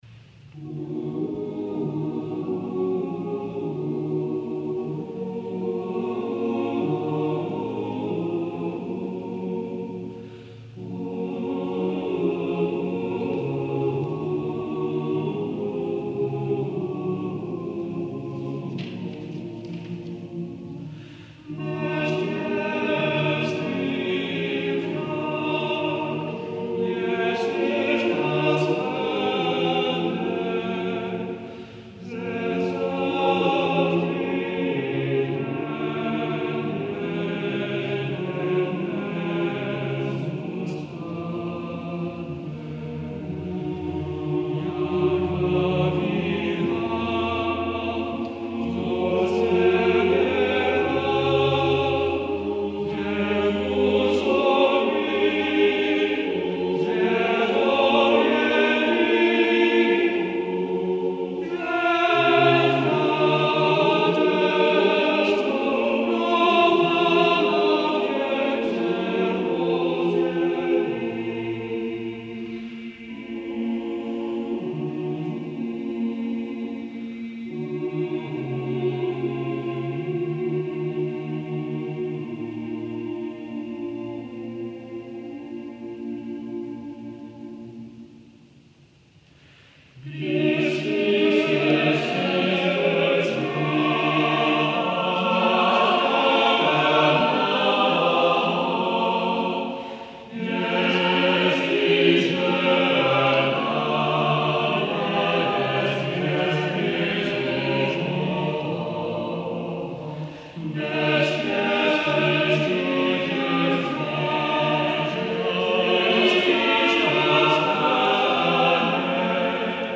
This beautiful Tenorlied is from the ‘Sbornik Dobrenského’, printed in Prague,1589.  The tenor melody is so good we thought we might be excused an accompanying “oo”.